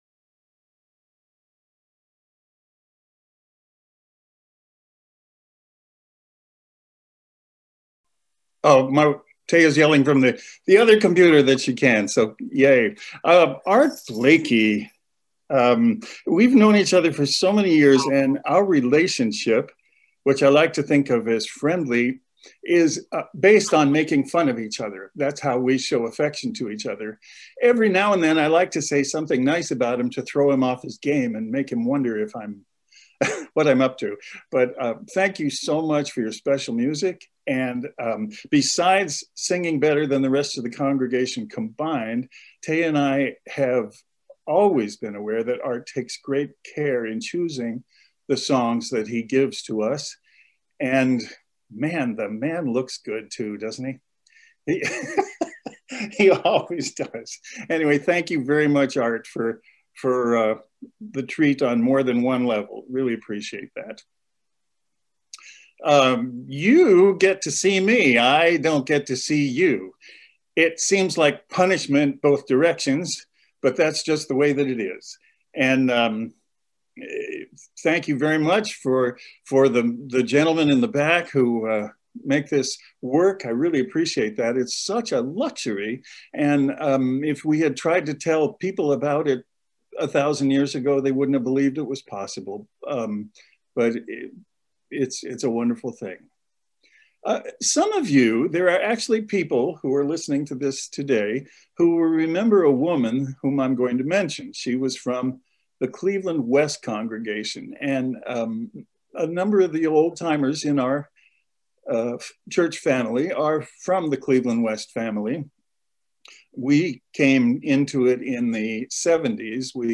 Given in Cleveland, OH
sermon_2-06-21.mp3